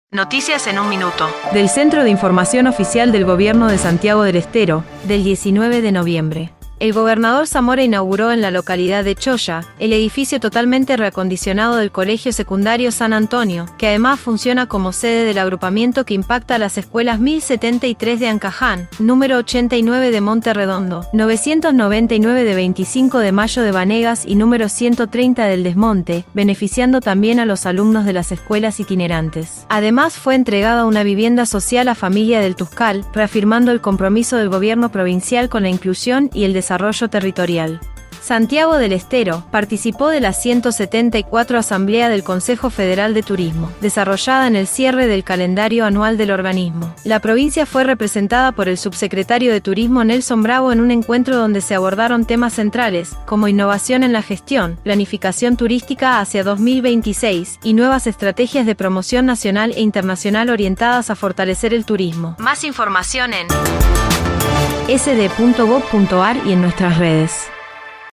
En 1 minuto el reporte de hoy